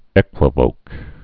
(ĕkwə-vōk, ēkwə-)